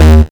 VEC1 Bass 212 D#.wav